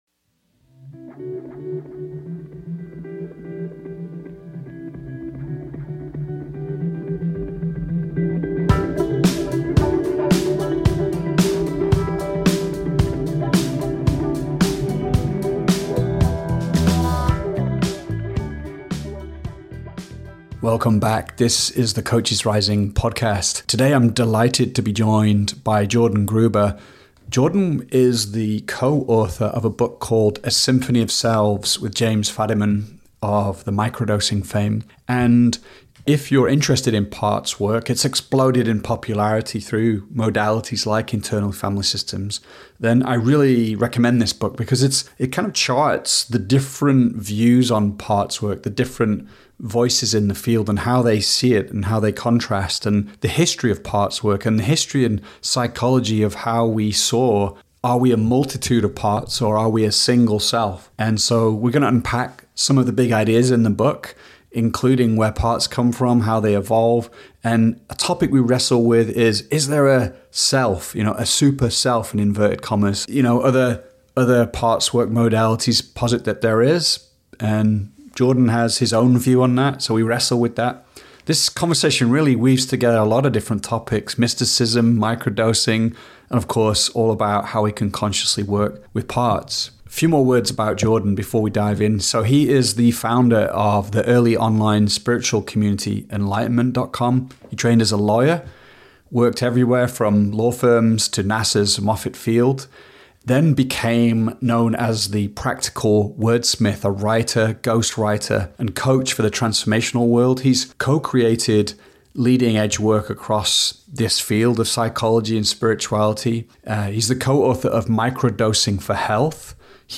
In this pivotal moment in humanity’s history, where the old structures are falling apart, how do we orient ourselves? What guidance is available to us, and how can we help people return to an inner source of wisdom? In this conversation